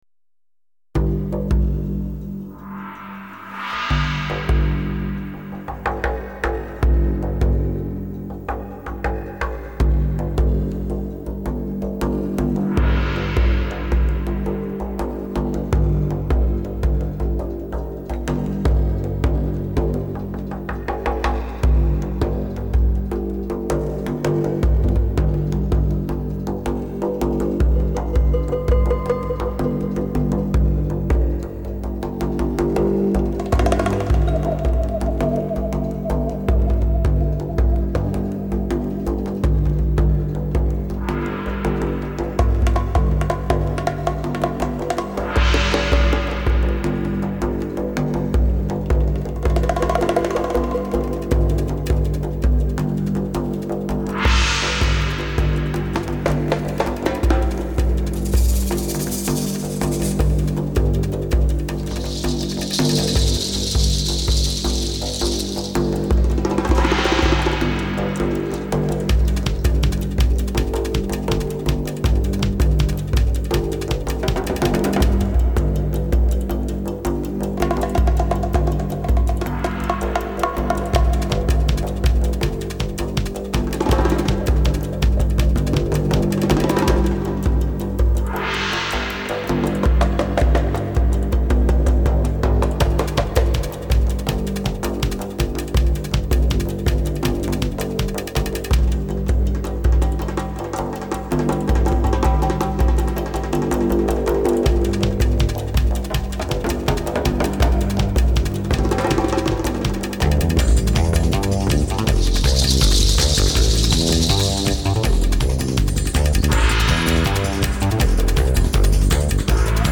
录音技术： DSD
温馨提示：日本JVC特别低音处理，试听时注意调节BASS（低音）。
缠绵悱恻的音色，搭配着热情澎湃的弦乐，低频气氛浓厚，音效震撼。